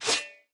Media:BarbarianKing_base_atk_4.wav 攻击音效 atk 初级和经典及以上形态攻击音效
BarbarianKing_base_atk_4.wav